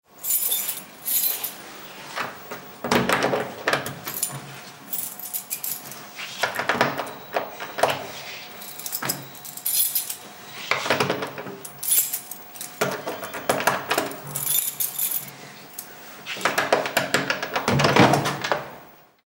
Intento de abrir una puerta blindada con unas llaves
cerradura
Sonidos: Acciones humanas
Sonidos: Hogar